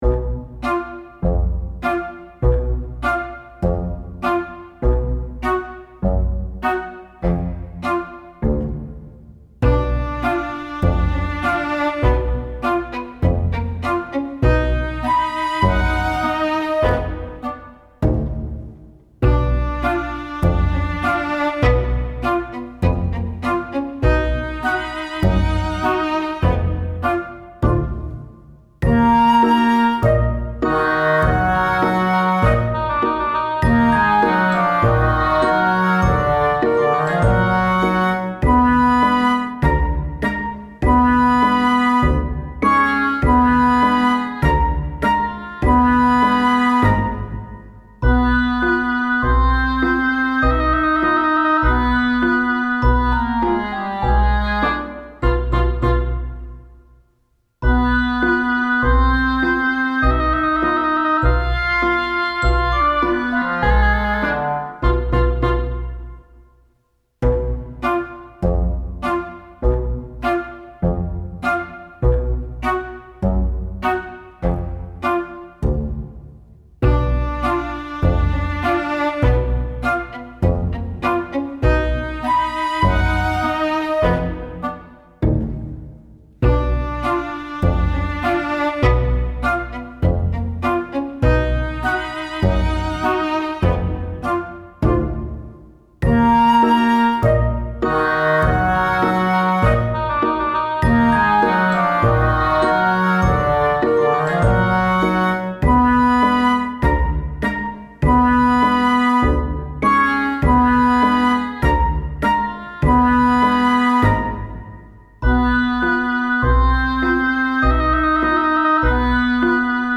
クラシカル 3:21